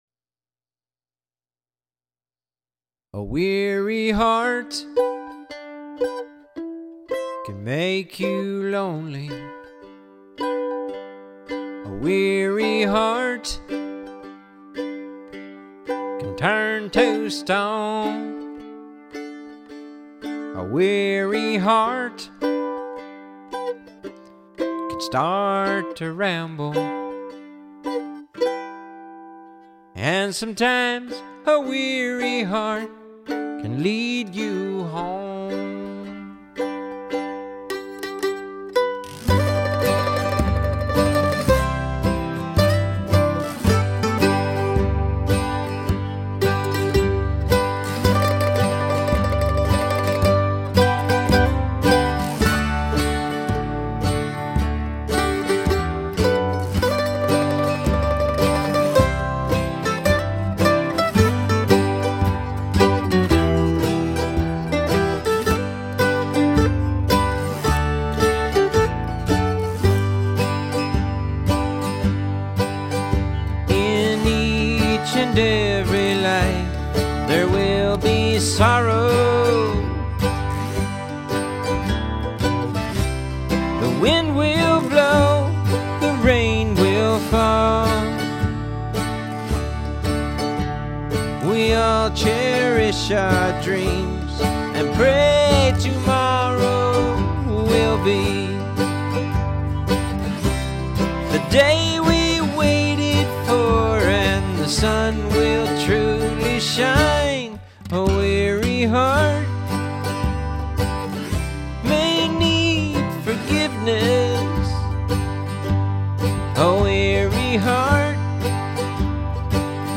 Weary-Heart-Me-Singing.mp3